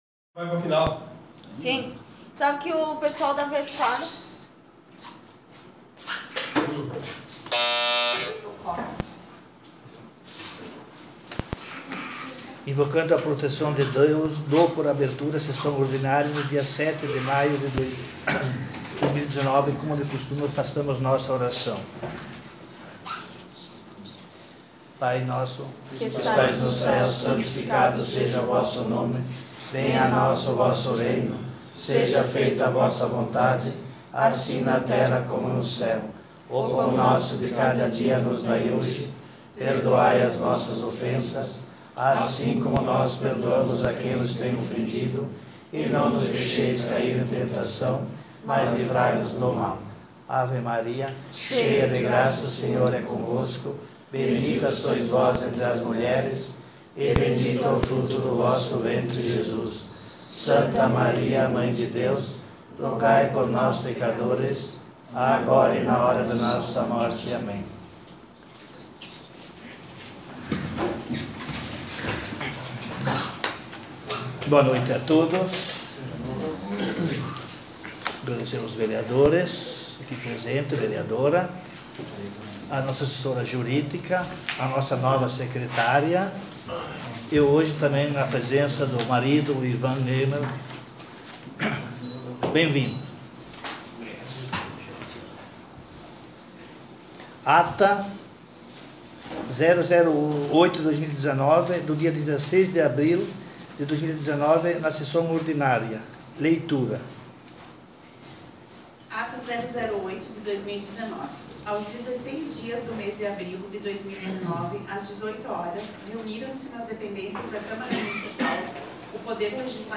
Sessão Ordinária dia 07/05/19